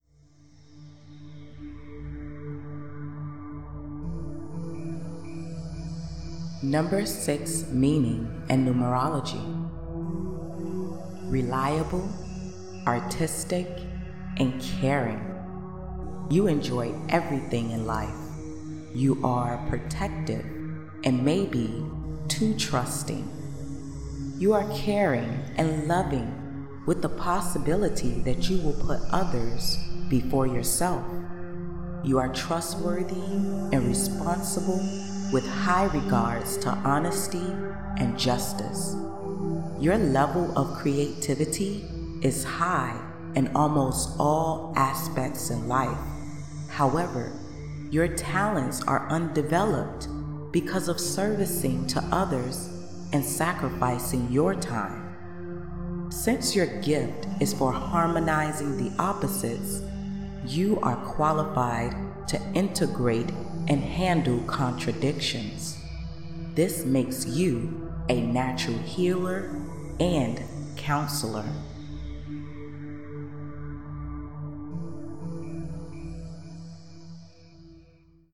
**** Below is a sample of the audio version of the Number Meanings.